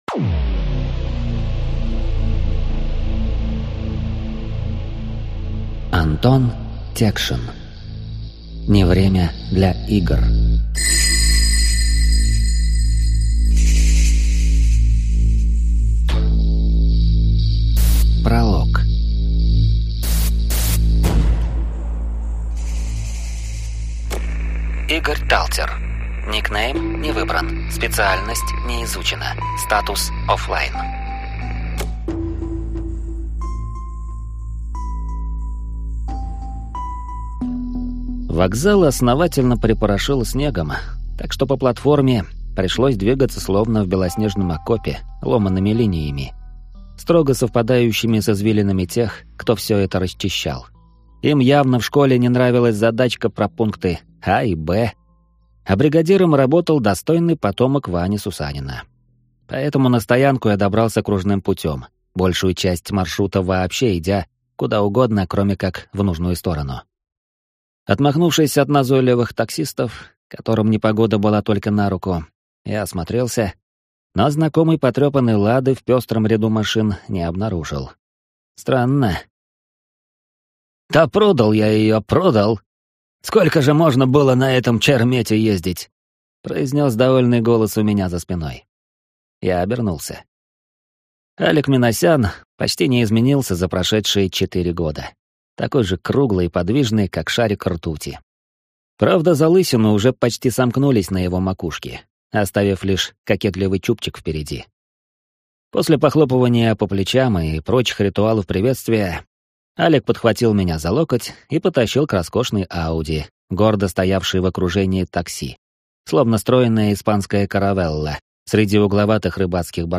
Аудиокнига Не время для игр | Библиотека аудиокниг